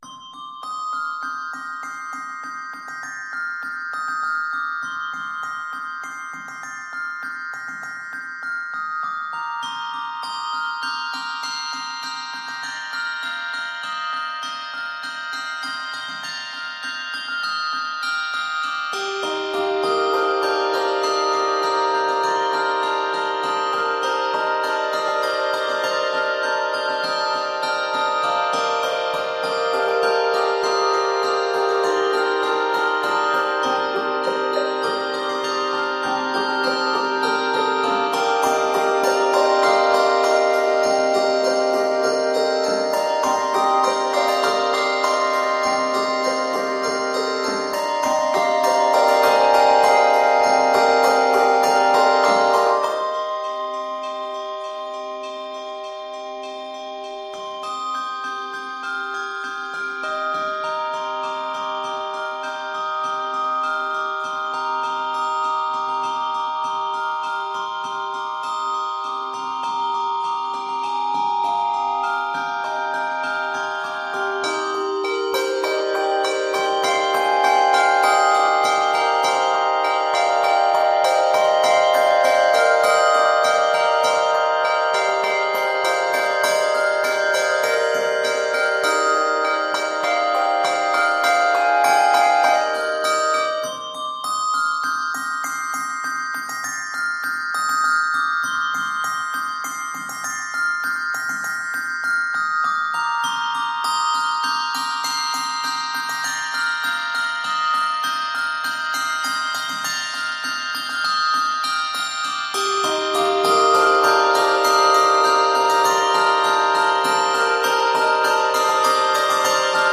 This score is 59 measures and is set in c minor.
Octaves: 3-5